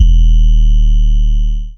Gamer World 808 3.wav